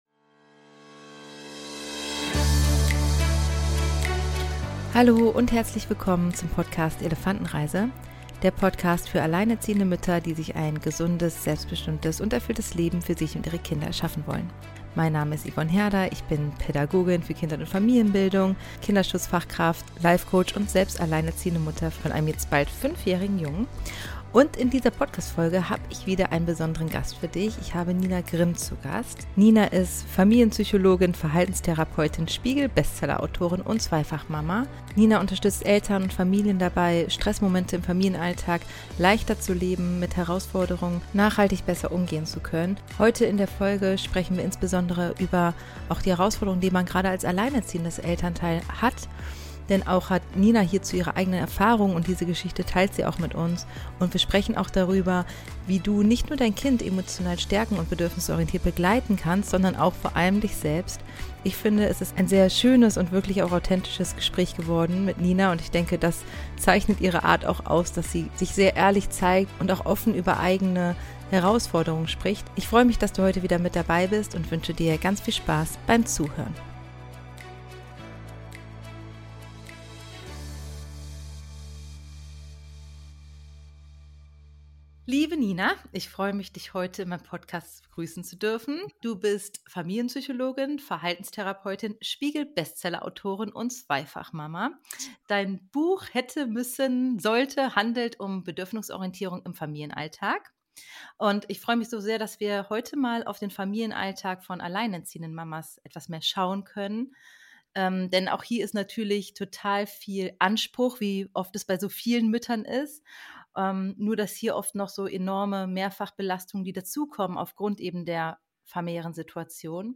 Im Gespräch mit Familienpsychologin